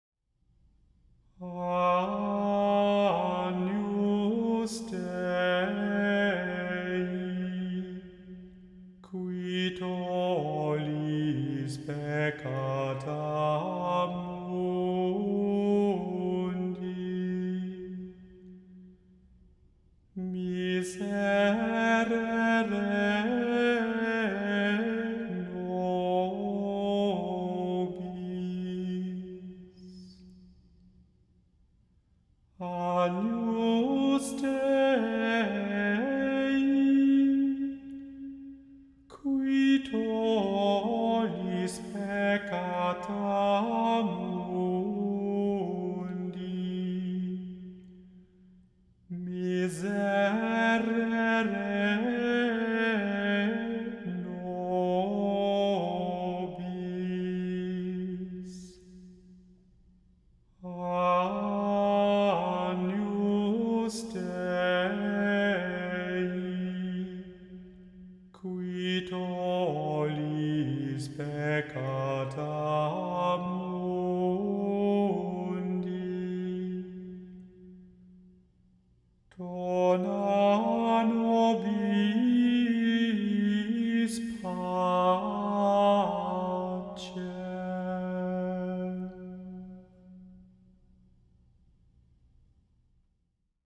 The Chant Project – Chant for Today (July 19) – Agnus Dei (Missa de Angelis) – Immanuel Lutheran Church, New York City